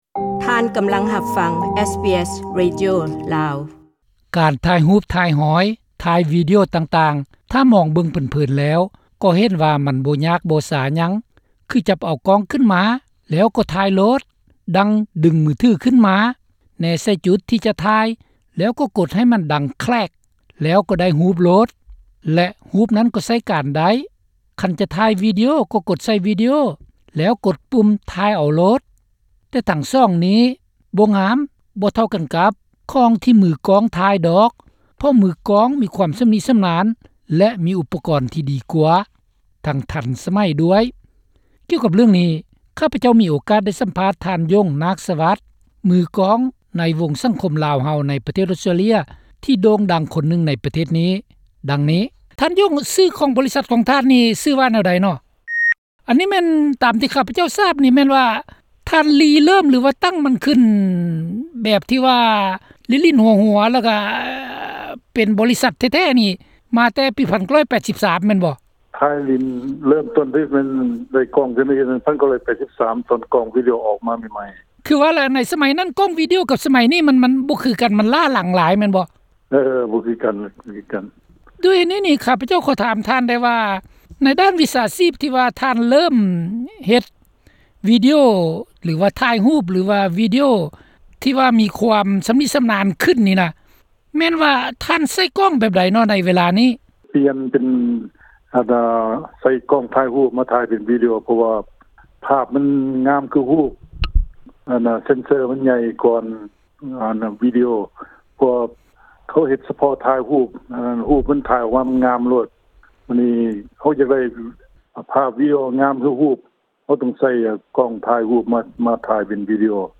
ການສຳພາດທ່ານຊີ້້ແຈງຫຼາຍສິ່ງຫຼາຍຢ່າງໃຫ້ຊາບວ່າ...